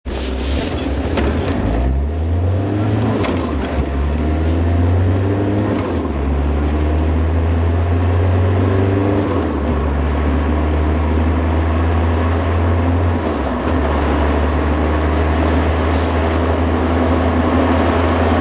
These were all recorded with the microphone inside the car, approximately where the front passenger's left ear would be.
ACCEL.MP3 (128,914 bytes) - Normally paced acceleration from 0 to 50 MPH. These recordings are a bit "bassy"; the exhaust rumble inside the car is not really as loud as it sounds here. You hear more of the engine than you do of the exhaust note.